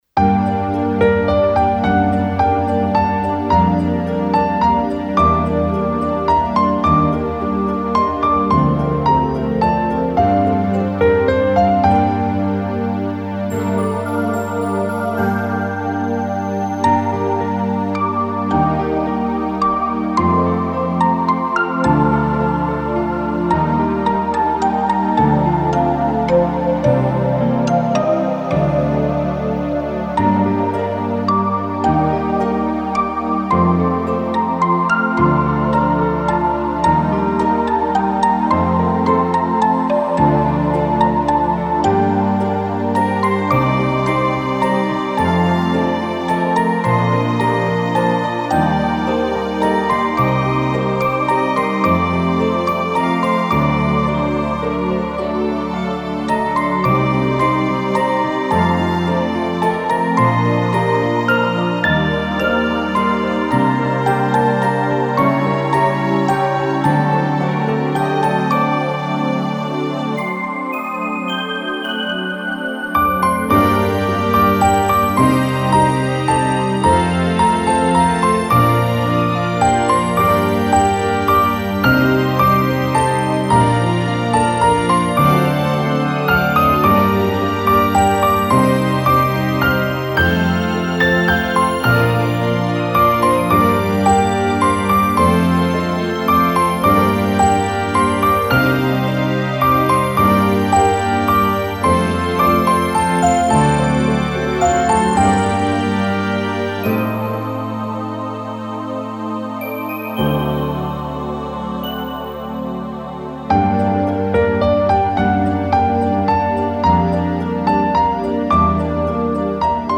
フリーBGM イベントシーン 切ない・悲しい
フェードアウト版のmp3を、こちらのページにて無料で配布しています。